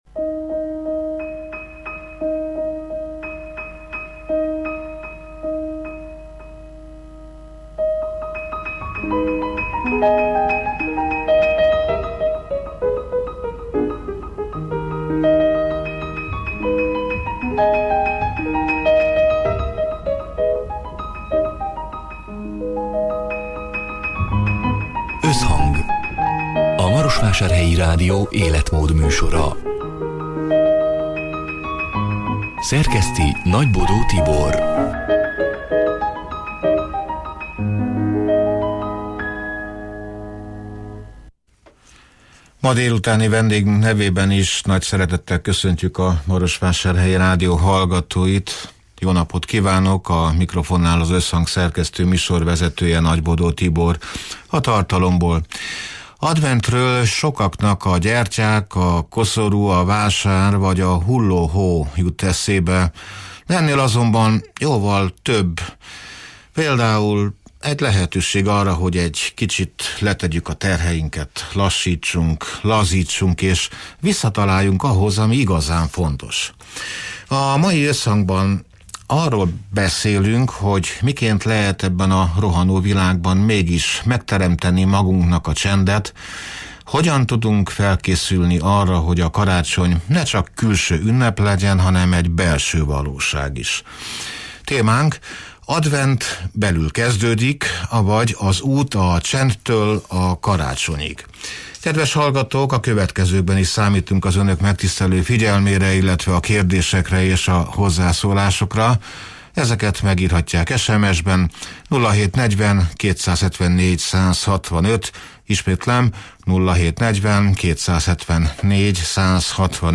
A Marosvásárhelyi Rádió Összhang (elhangzott: 2026. november 26-án, szerdán délután hat órától élőben) című műsorának hanganyaga: